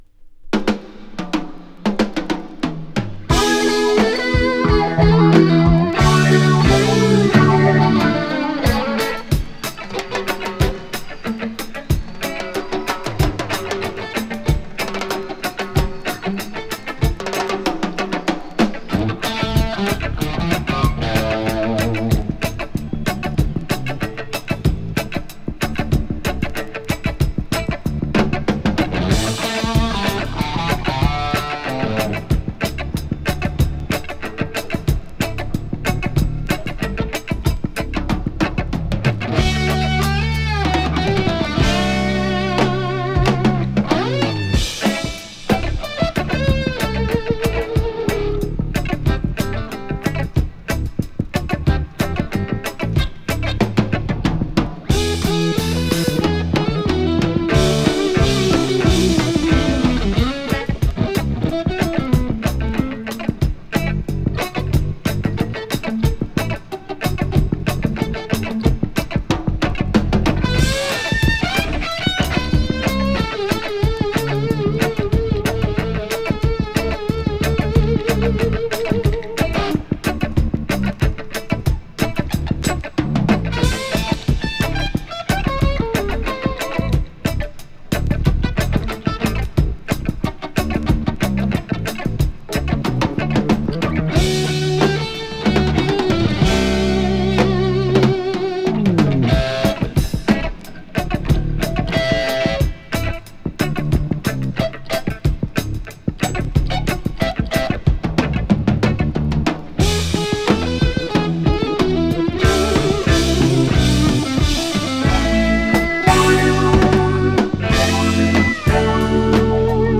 > PSYCHEDELIC/PROGRESSIVE/JAZZ ROCK